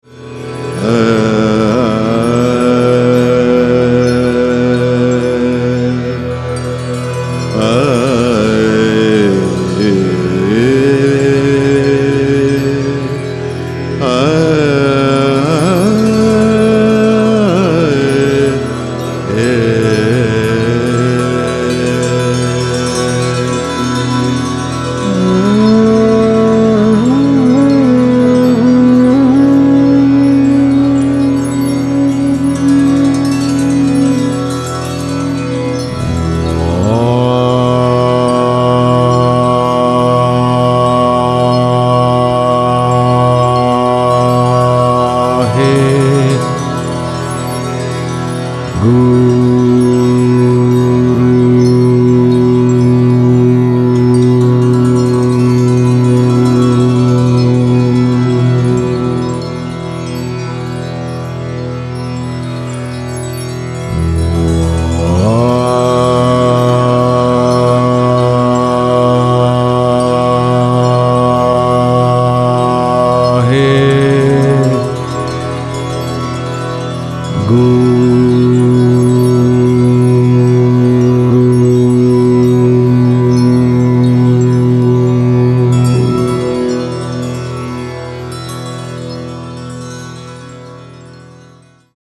The resonant lead voice
and brings a serene and rich meditative bliss.
vocalist
Total immersive soundscapes
haunting bansuri playing